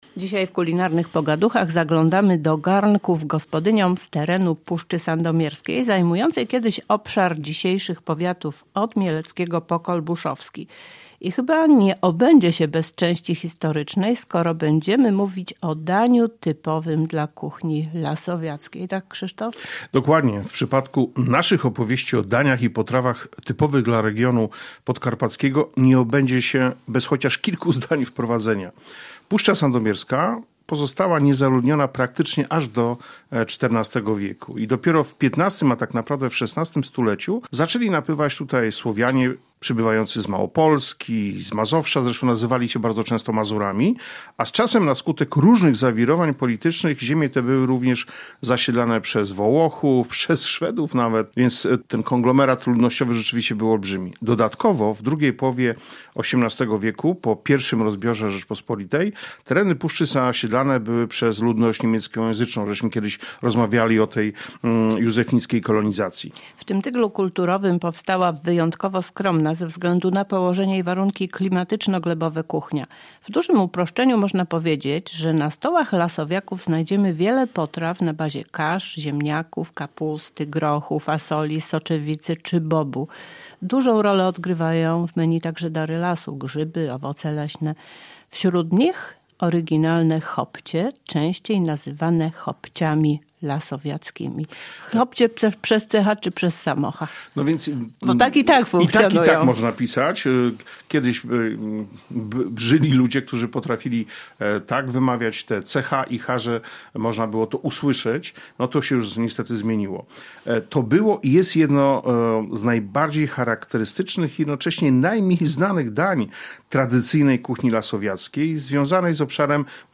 Więcej na temat tej potrawy i nie tylko – w rozmowie